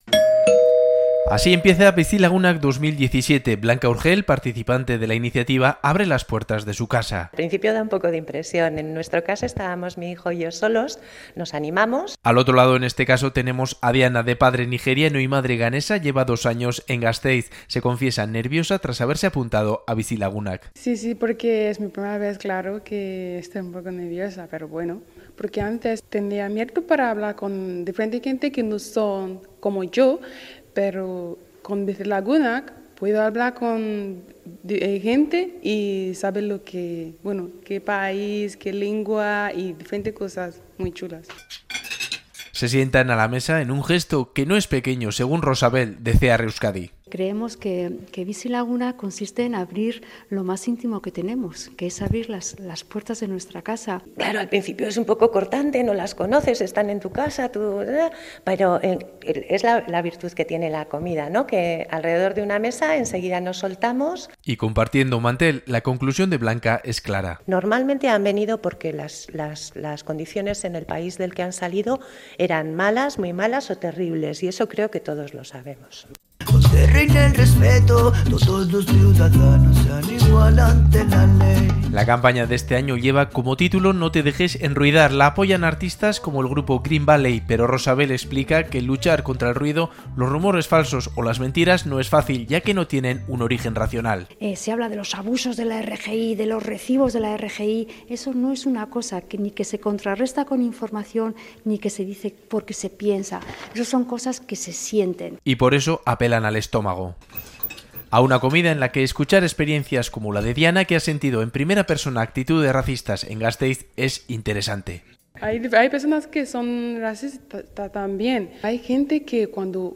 REPORTAJES